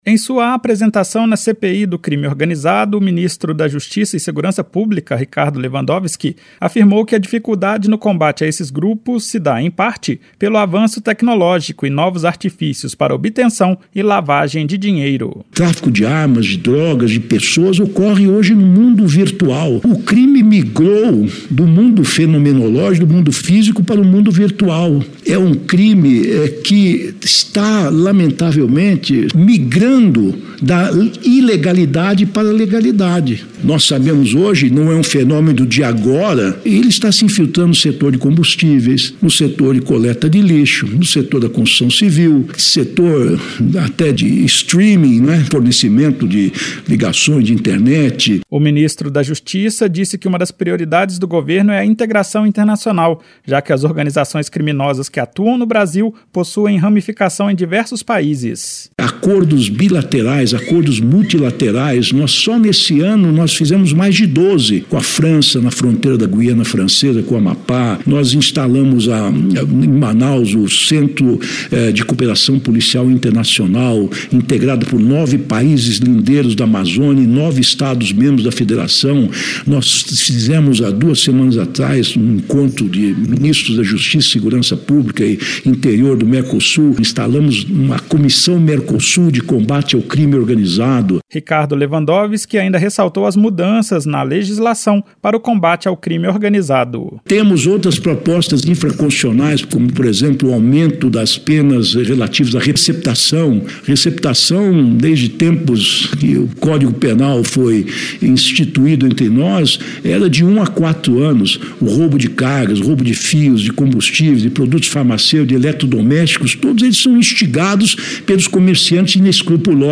Em participação na CPI do Crime Organizado, o ministro da Justiça, Ricardo Lewandowski, apontou que facções têm usado o mundo virtual para cometer crimes como o de tráfico de armas, drogas e pessoas. Ele explicou que esses grupos ampliaram sua atuação em setores legais para lavar dinheiro.